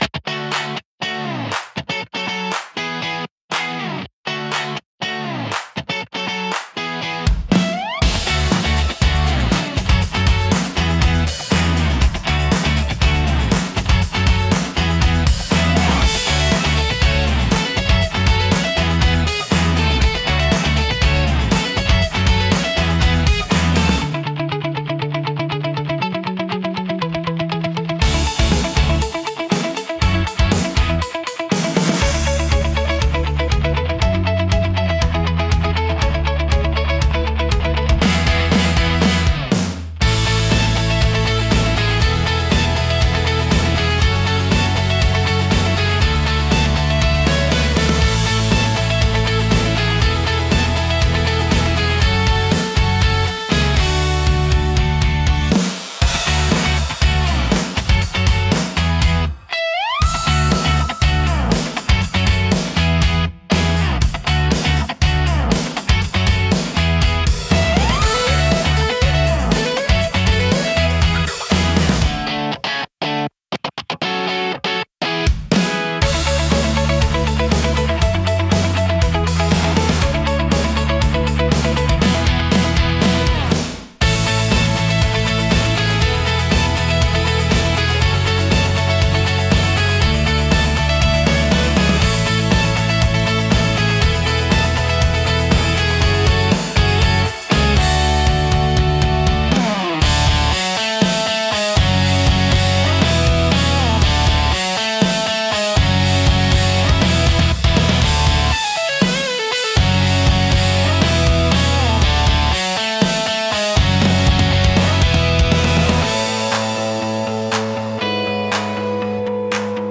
感情の高まりやポジティブな気分を引き出しつつ、テンポ良く軽快なシーンを盛り上げる用途が多いです。